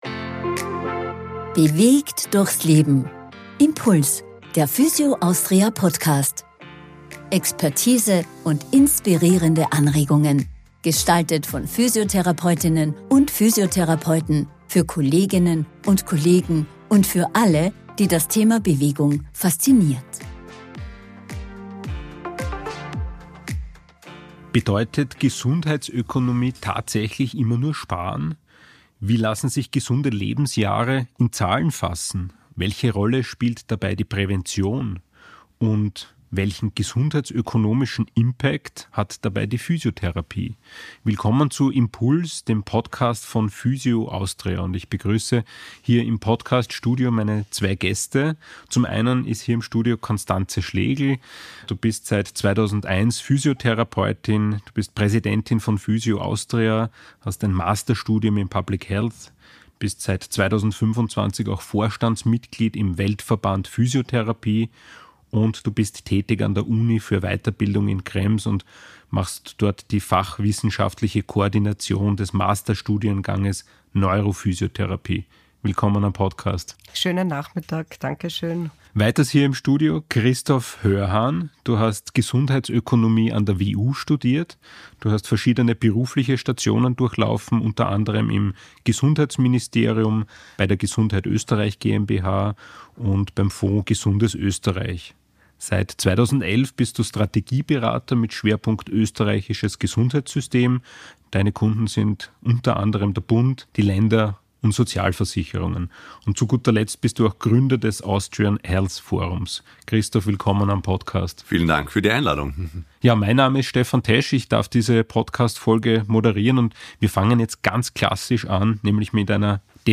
Studiogespräch